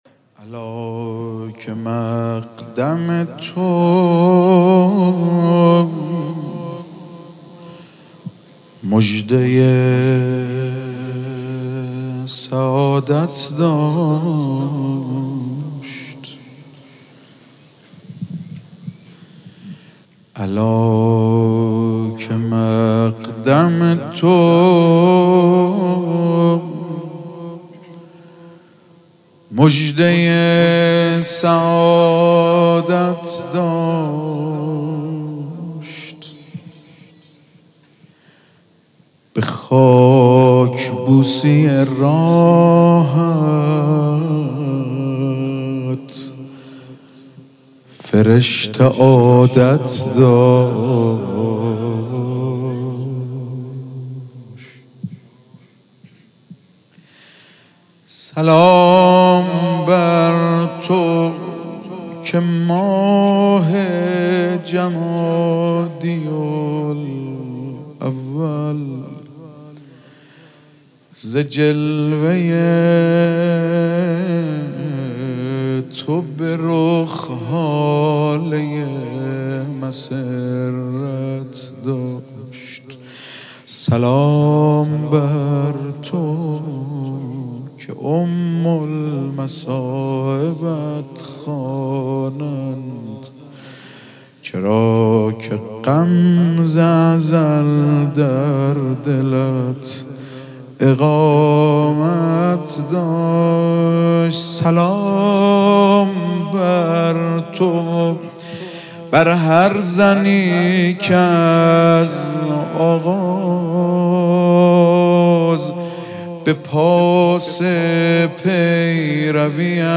مراسم جشن ولادت حضرت زینب (سلام الله علیها)
‌‌‌‌‌‌‌‌‌‌‌‌‌حسینیه ریحانه الحسین سلام الله علیها
مدح